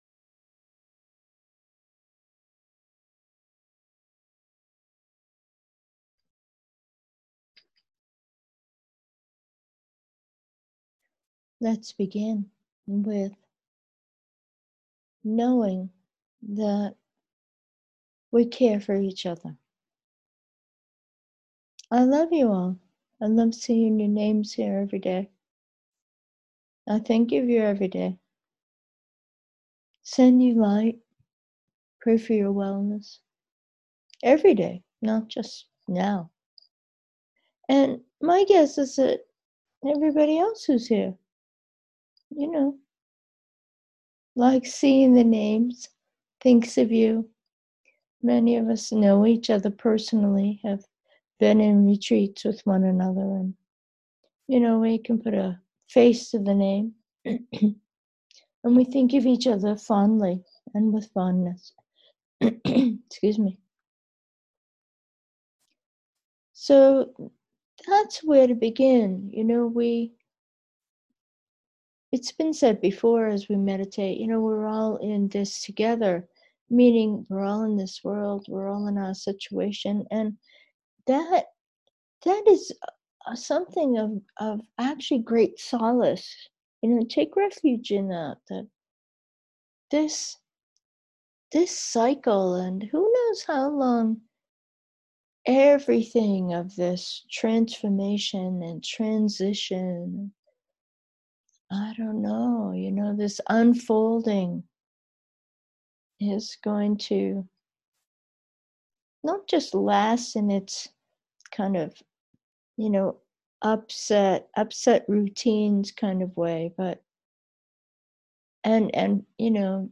Meditation: birthing